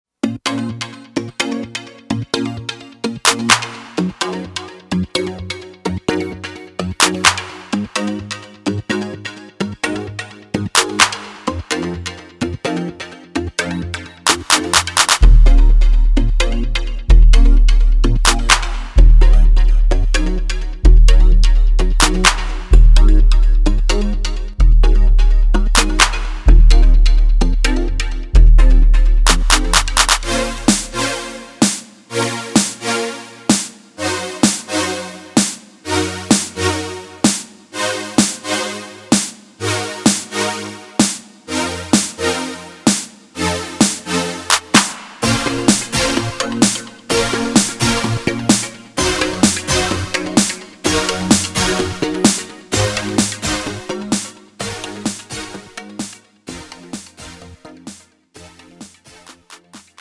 장르 가요 구분 Premium MR